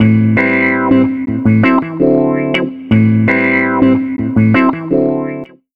09 SummerVibe 165 Amin.wav